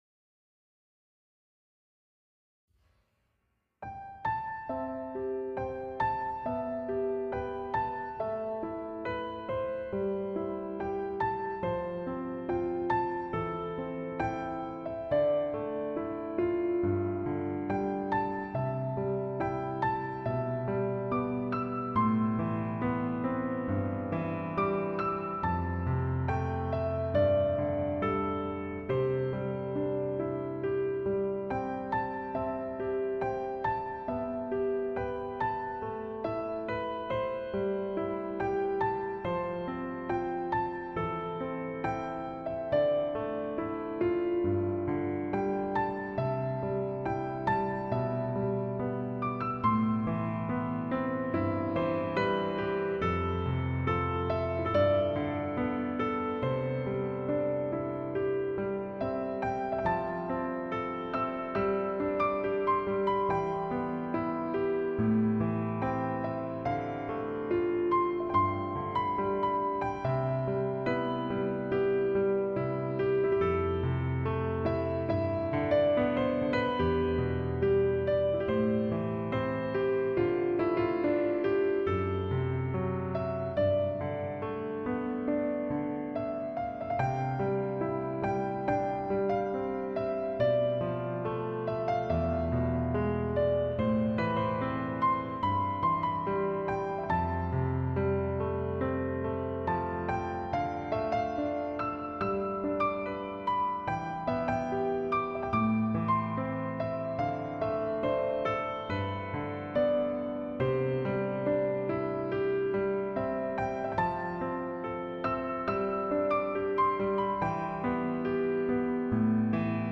夜的钢琴曲中的每一首曲子都静谧舒缓，让彼此心灵诗意地栖息。